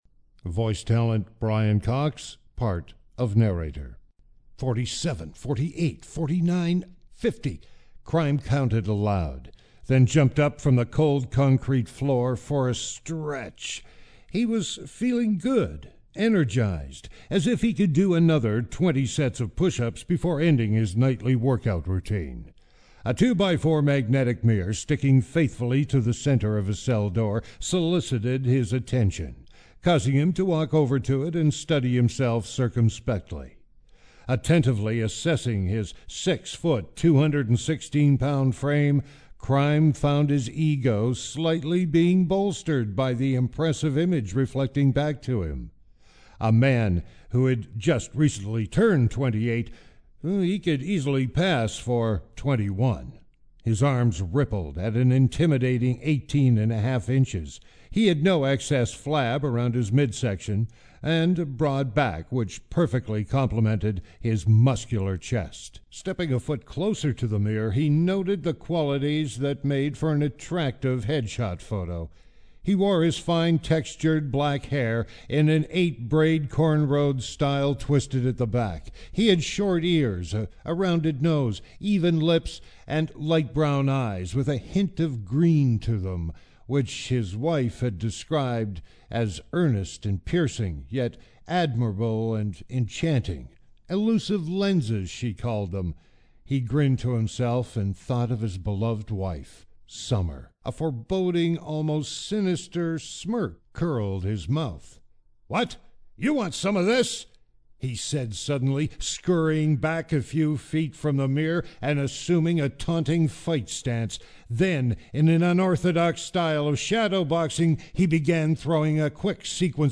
Tags: Voice Advertising Voice Actor Voice Over Media